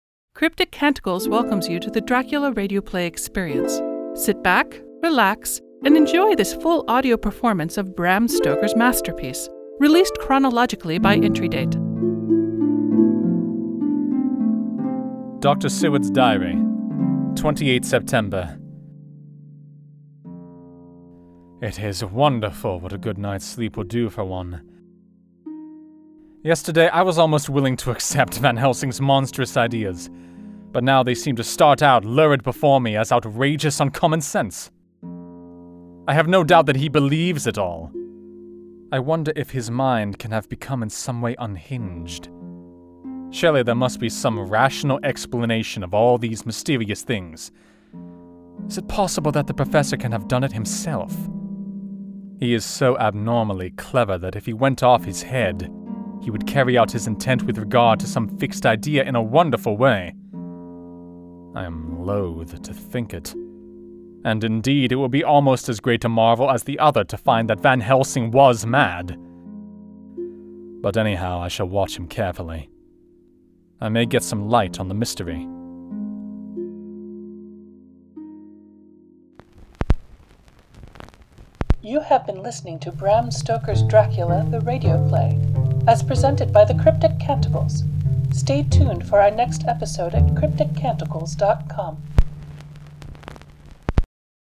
Dr Seward